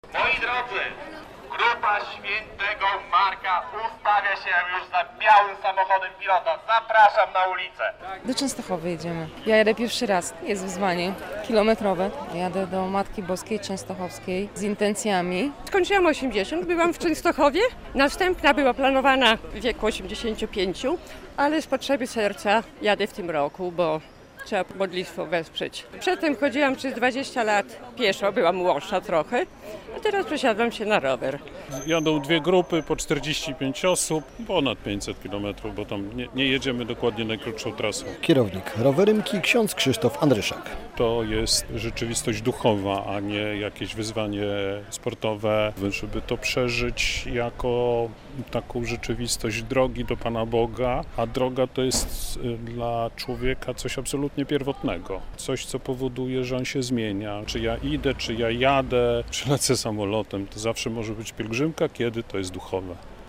Trwa rowerowa pielgrzymka na Jasną Górę - relacja